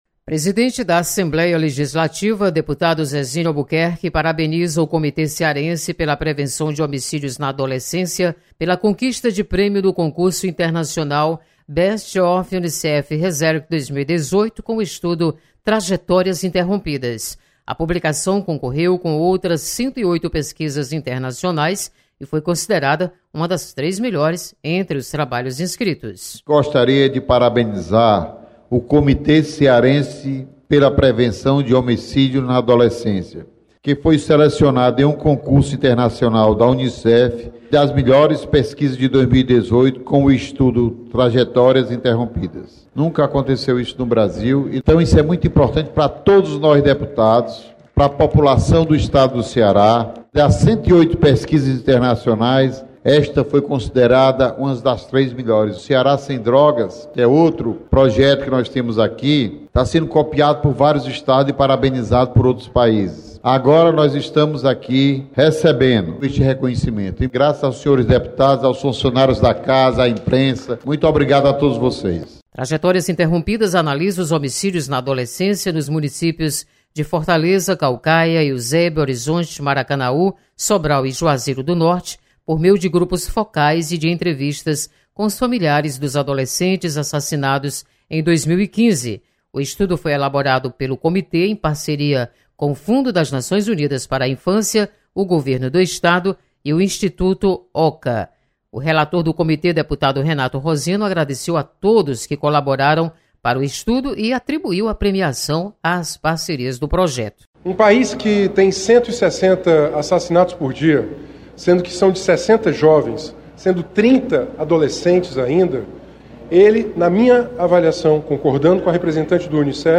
Comitê Cearense pela Prevenção de Homicídios na Adolescência recebe prêmio do Unicef. Repórter